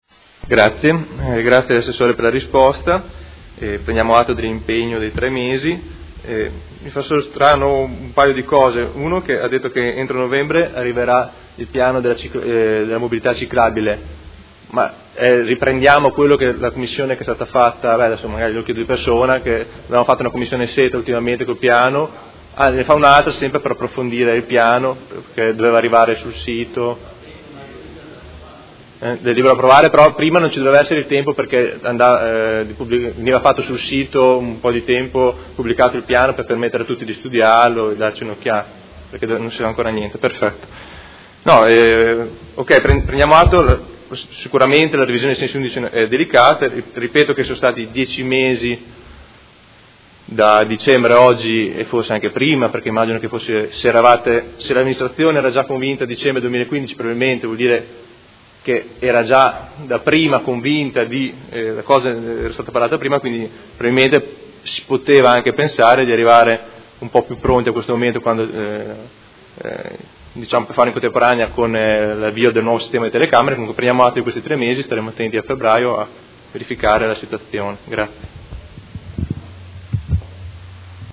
Marco Rabboni — Sito Audio Consiglio Comunale